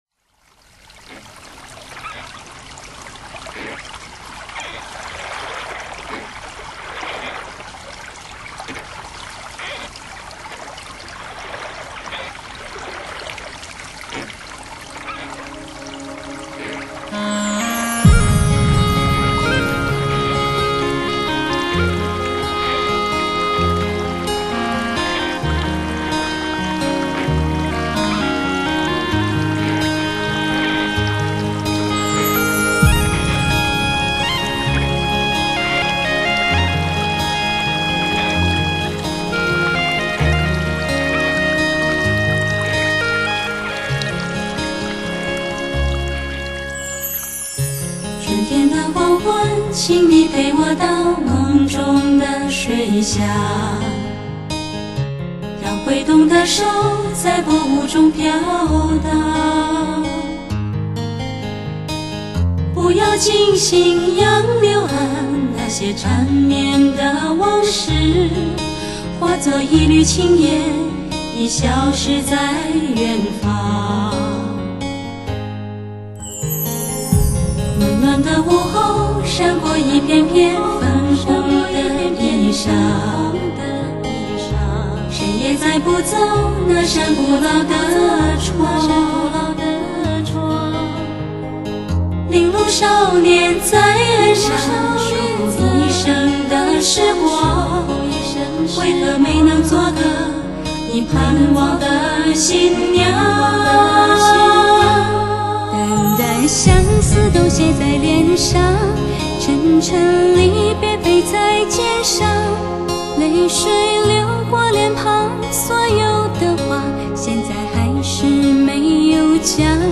全新数码录音，音响效果新碟霸，词、意、唱溶为一体。